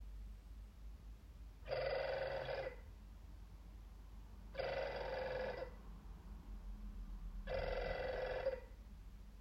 Ma pompe de relevage fait beaucoup de bruit en ce moment, surtout à l'arrêt.
Bruit pompe de relevage
Ce qui me chiffonne ce n'est pas qu'elle se déclenche mais son bruit plutôt strident (cf enregistrement) et qu'elle fonctionne surtout clim éteinte.
Il est totalement anormal qu'une pompe se mette en marche toutes les 2 ou 3 secondes.